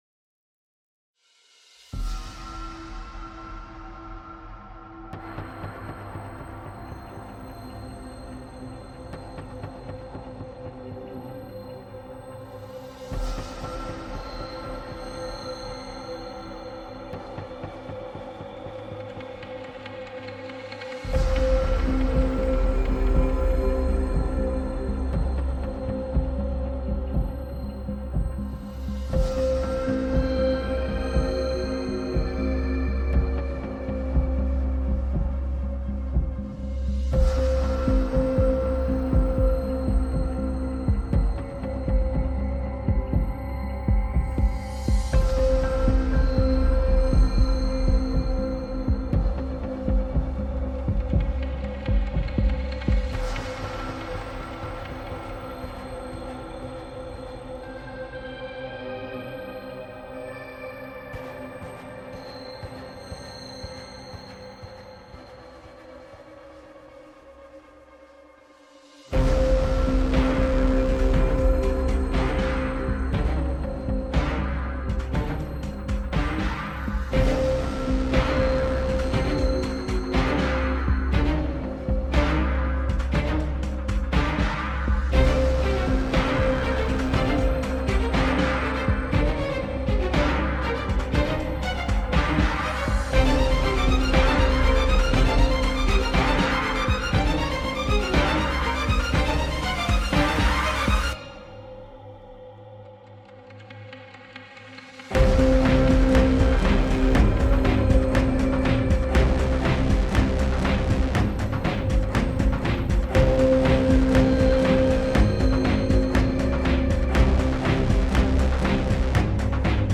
tema dizi müziği, heyecan gerilim aksiyon fon müzik.